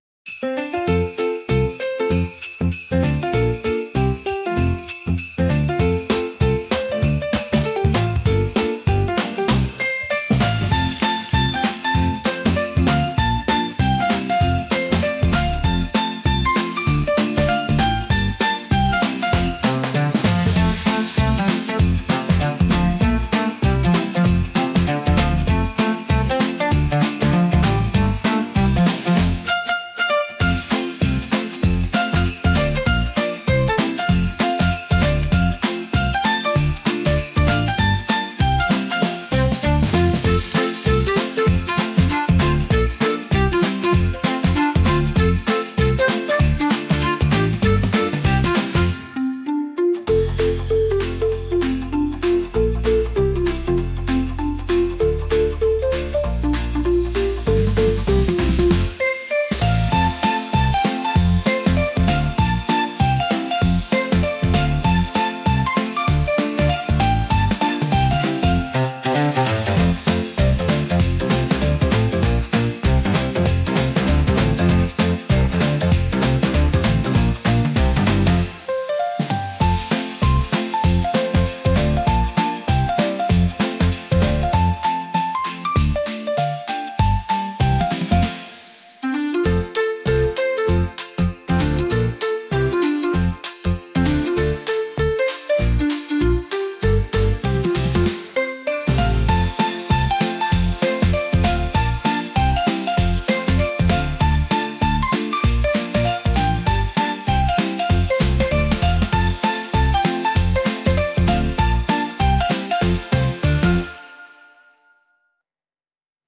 タイトル通り、キッズやチャイルド世代（幼児〜低学年）のために作られた、可愛らしさ満点の一曲！